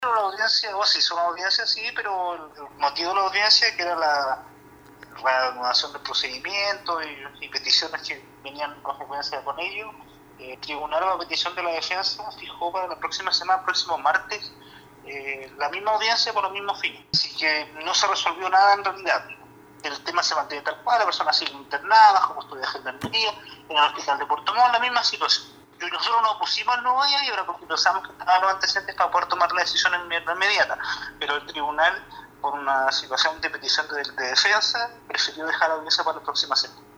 Según indicó a radio Estrella del Mar el fiscal Javier Calisto, este joven aun continúa en esa condición, es decir en internación en la dependencia hospitalaria, ya que este lunes en la audiencia y a petición de la defensa, la causa recién se verá nuevamente la próxima semana.
10-FISCAL-AUDIENCIA-IGLESIA.mp3